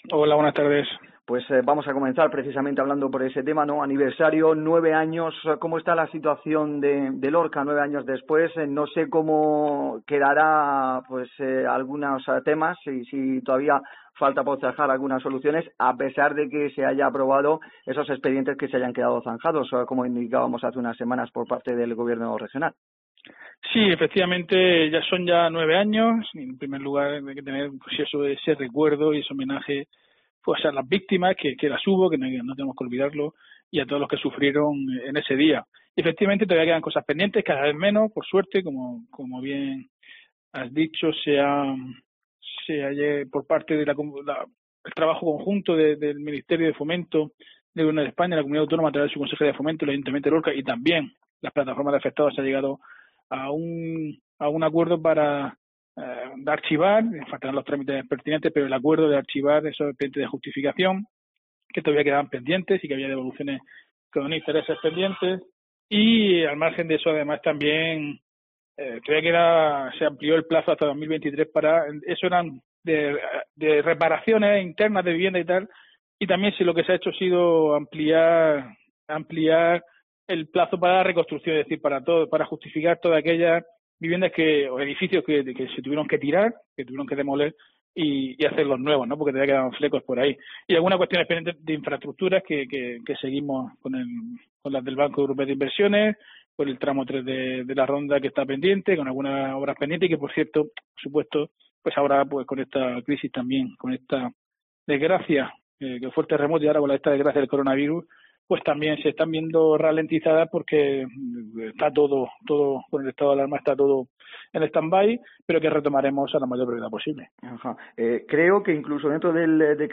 Diego José Mateos, alcalde de Lorca en COPE